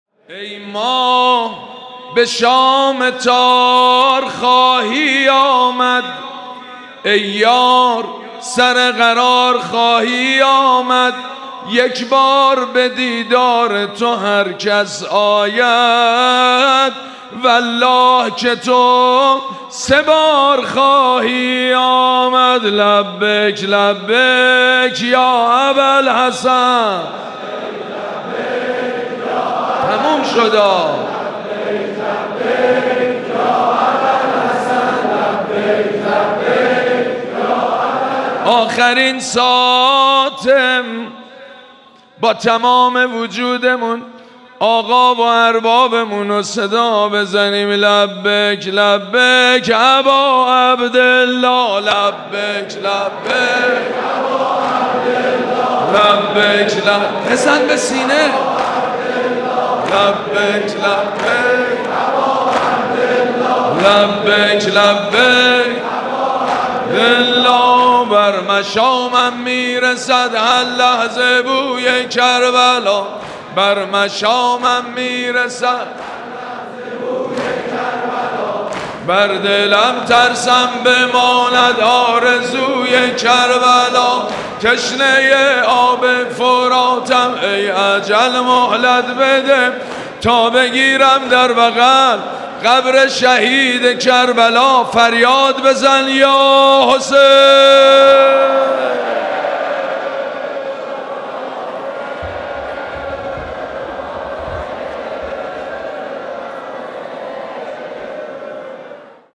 مراسم عزاداری ظهر سی‌ام ماه صفر
حسینیه امام خمینی (ره)
مداح
حاج سید مجید بنی فاطمه